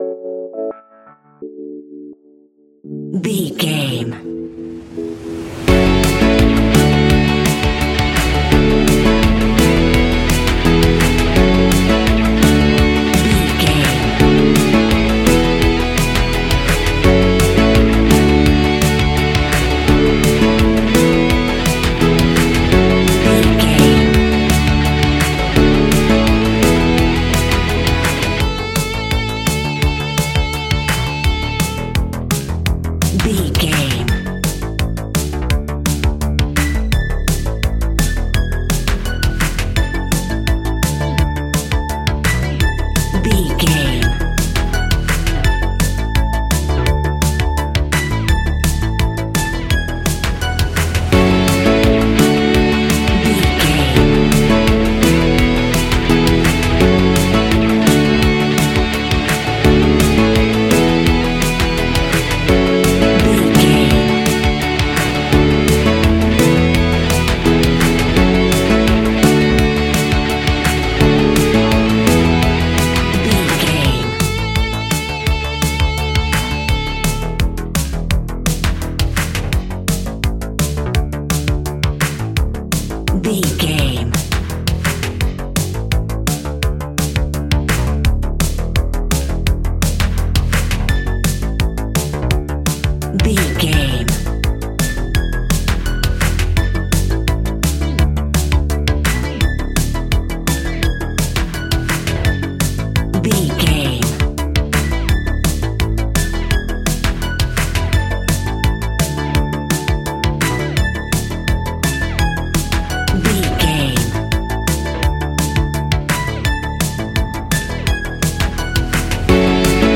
Ionian/Major
ambient
electronic
new age
chill out
downtempo
pads
drone